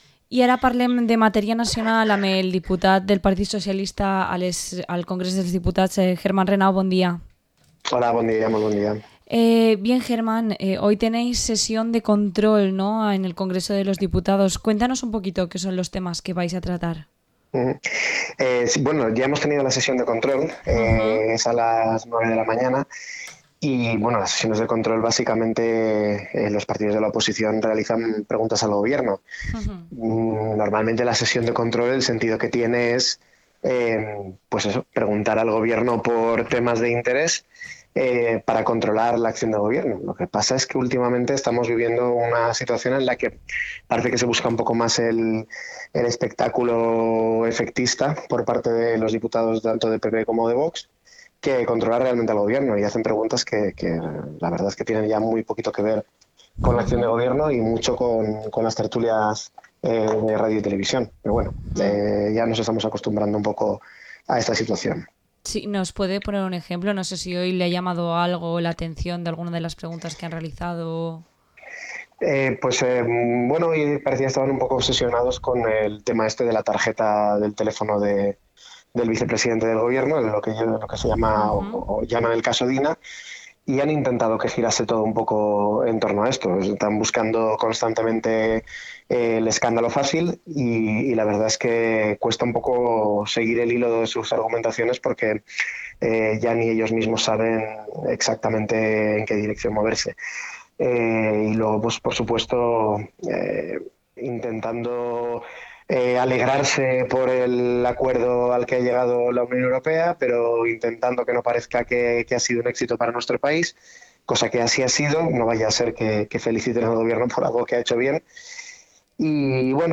Entrevista al diputado nacional del PSPV-PSOE, Germán Renau